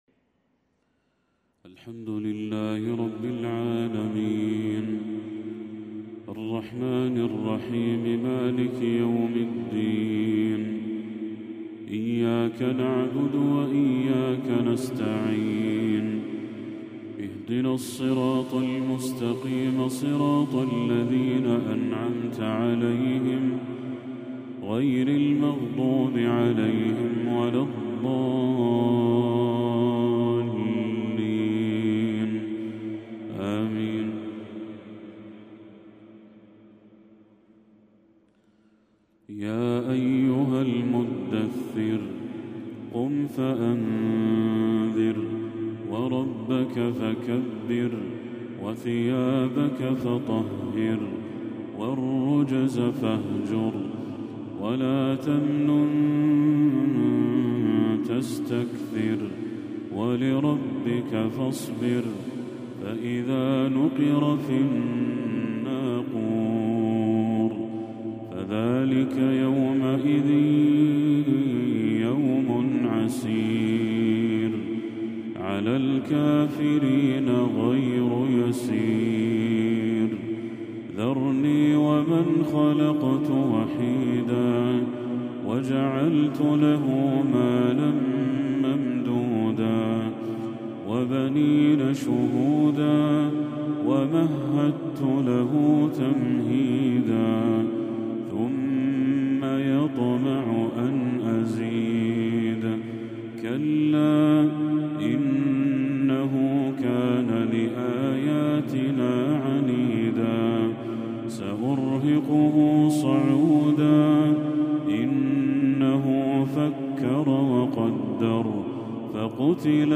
تلاوة خاشعة لسورة المدثر كاملة
عشاء 25 ربيع الأول 1446هـ